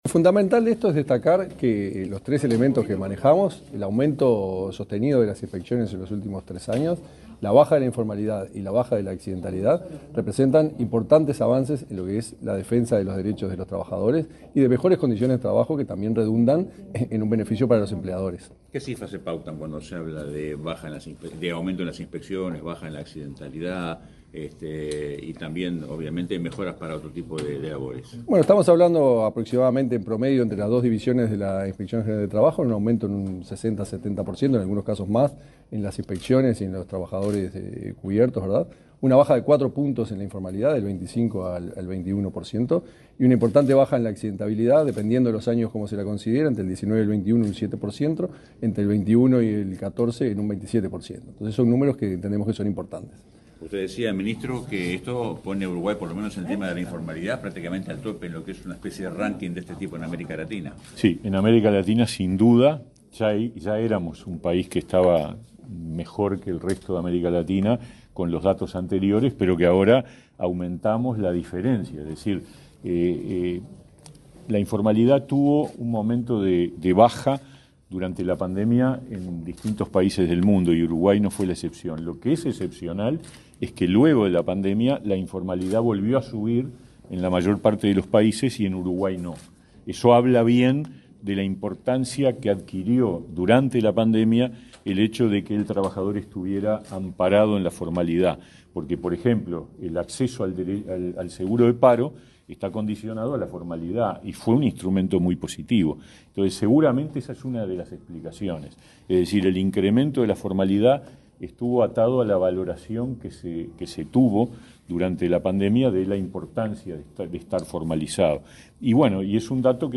Declaraciones a la prensa de autoridades del Ministerio de Trabajo
Declaraciones a la prensa de autoridades del Ministerio de Trabajo 22/11/2022 Compartir Facebook X Copiar enlace WhatsApp LinkedIn Este martes 22, el ministro de Trabajo, Pablo Mieres, y el inspector general del Trabajo, Tomás Teijeiro, informaron sobre avances registrados en la protección de los derechos de los trabajadores. Luego ambos jerarcas dialogaron con la prensa.